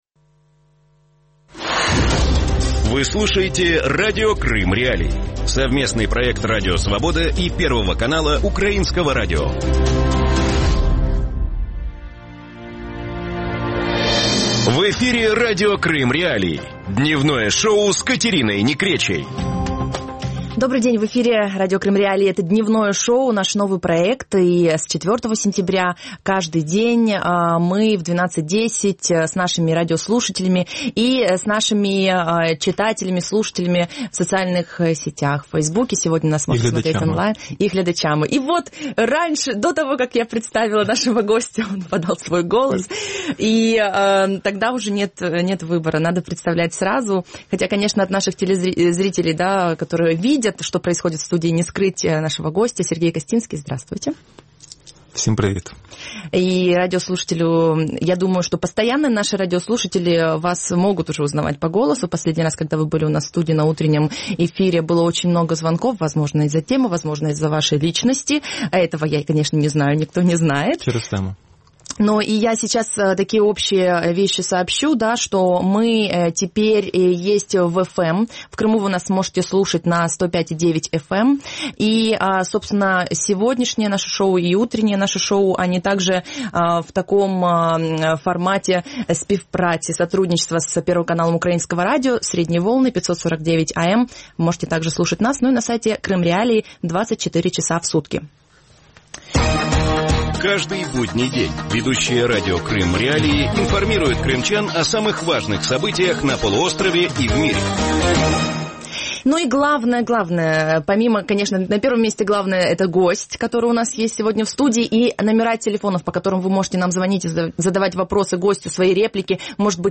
Почему Киев хочет восстановить вещание украинских телеканалов и радиостанций на аннексированный Крым? А также в эфире будут звучать вопросы читателей и слушателей Крым.Реалии. Гость «Дневного шоу» на Радио Крым.Реалии – член Национального совета по вопросам телевидения и радиовещания Украины Сергей Костинский.